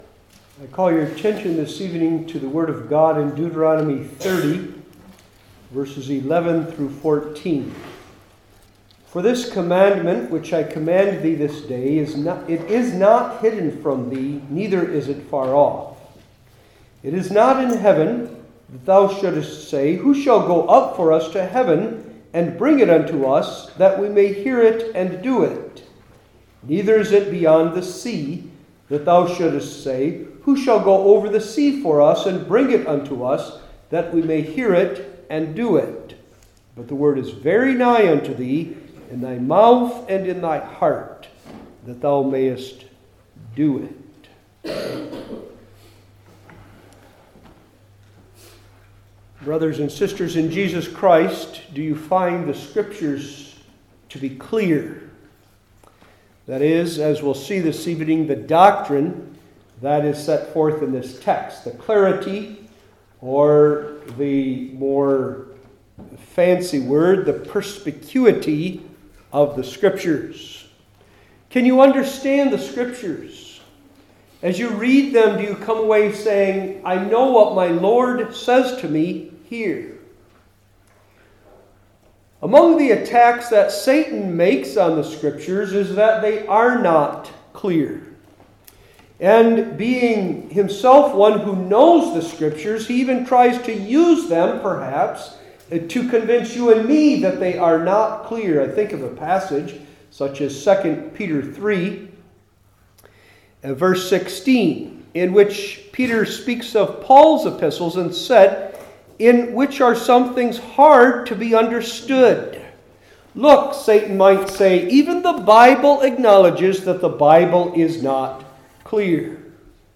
Old Testament Individual Sermons I. The Word That Is Near II.